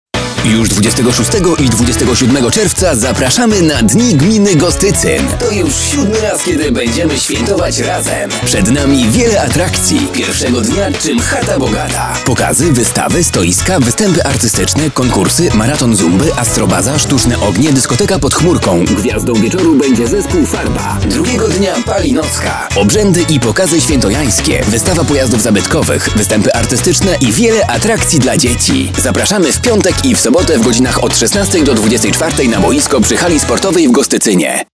dni_gminy_gostycyn_spot.mp3